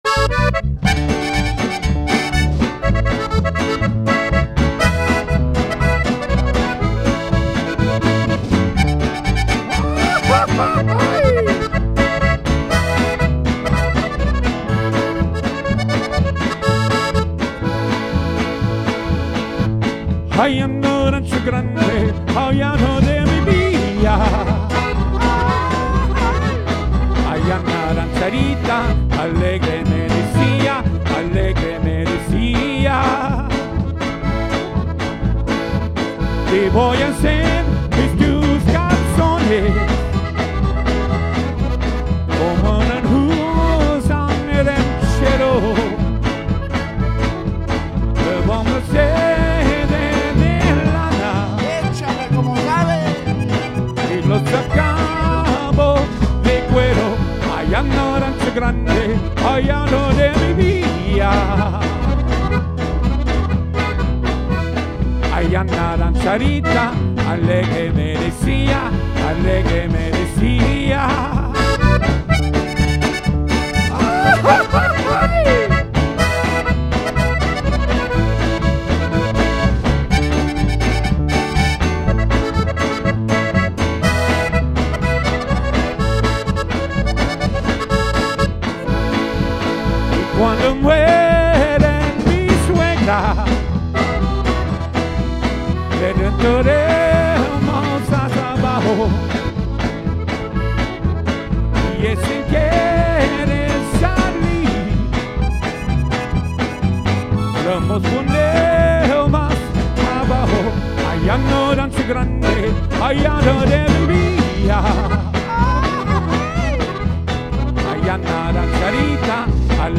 Tejano-rock